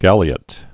(gălē-ət)